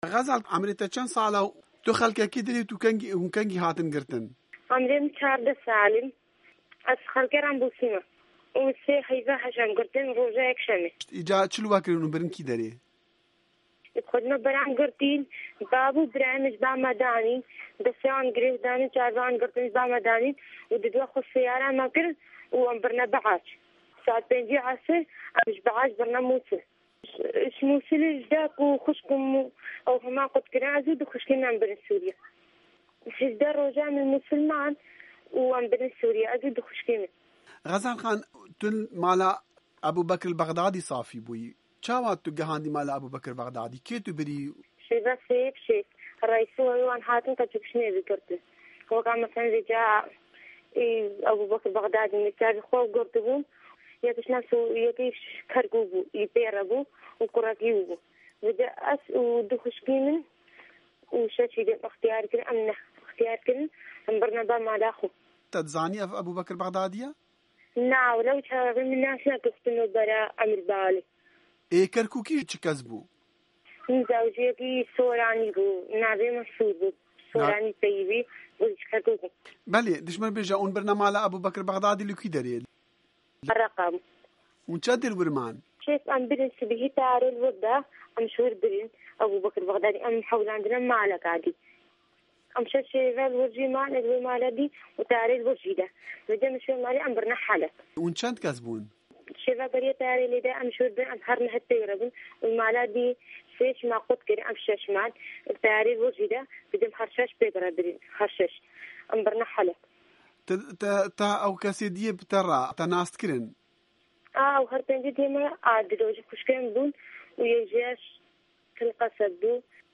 Keçeka dî ya Êzdî, koleya mala Ebu Bekir Bexdadî çîroka xwe ji Dengê Amerîka re vegêra û dibêje, wî rêberê komela Dewleta Îslamî DAIŞ li wê û hevalên wê dixist û gefên destdirjêjiya zayindeyî û marekirinê li wan dixwarin, lê wan şiya xwe rizgar bikin.